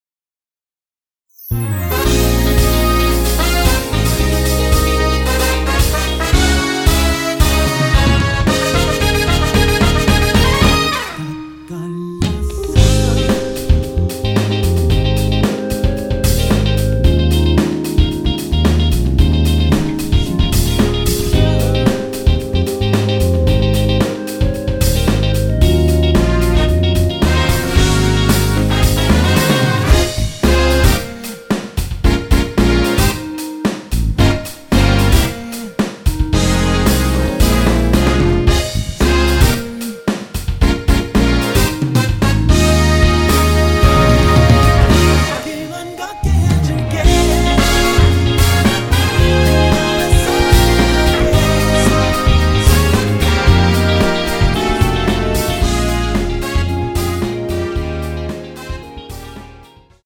원키 멜로디와 코러스 포함된 MR 입니다.(미리듣기 참조)
Ab
앞부분30초, 뒷부분30초씩 편집해서 올려 드리고 있습니다.
중간에 음이 끈어지고 다시 나오는 이유는